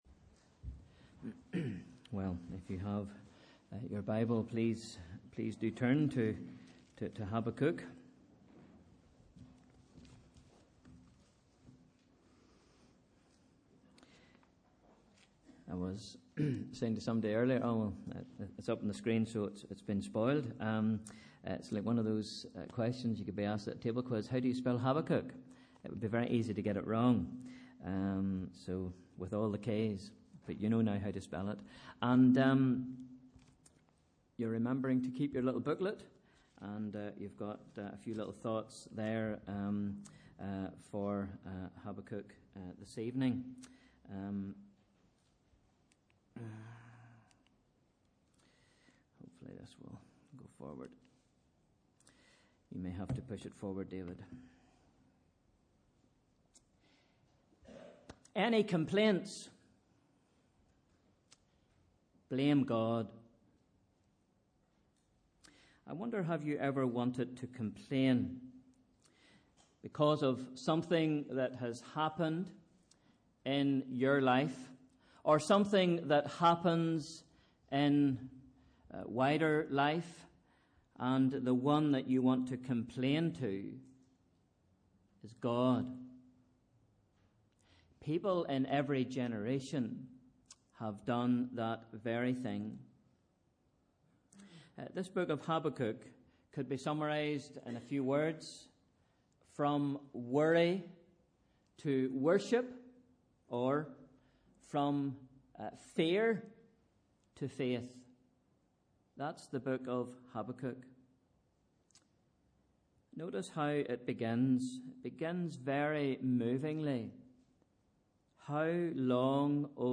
Sunday 28th June Evening Service @ 7:00pm